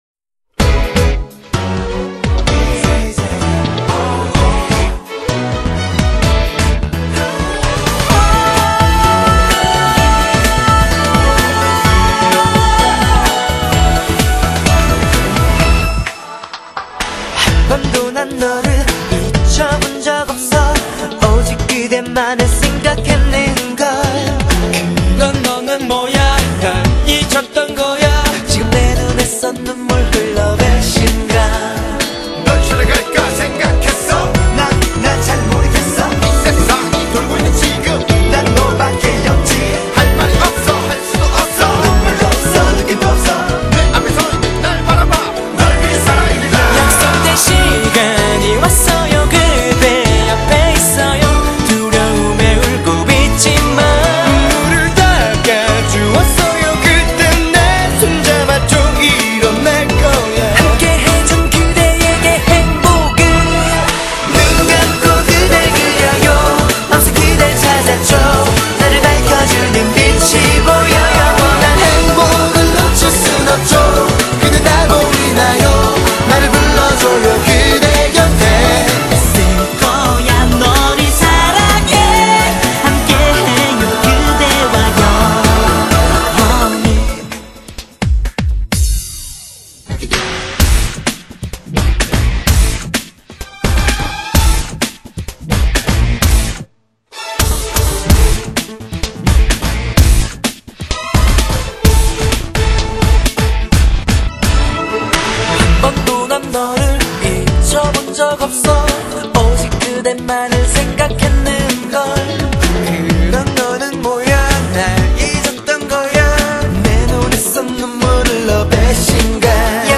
BPM128--1
Audio QualityPerfect (High Quality)